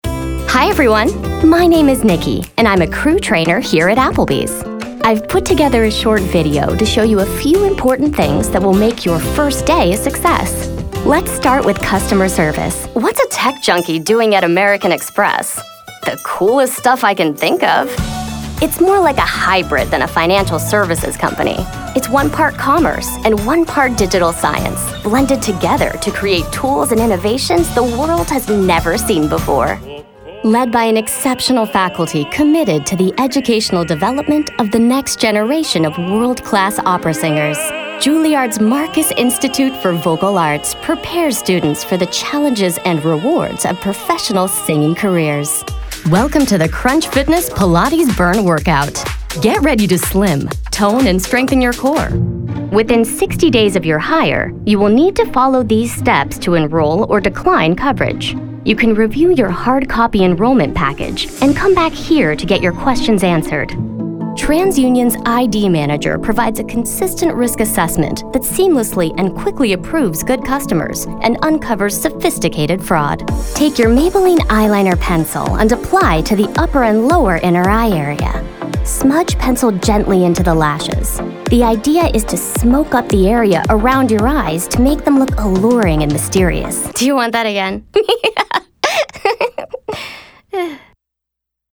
All of our contracted Talent have broadcast quality home recording studios.
Corporate Narration Video Demos Alabama Department of Early Childhood Education Back to Voiceover Talents